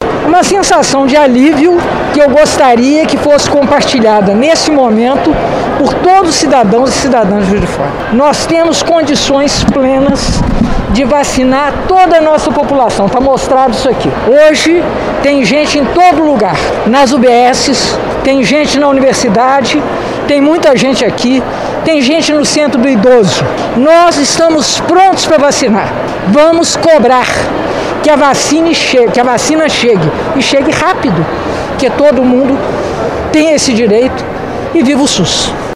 Após a imunização, Margarida falou com a imprensa.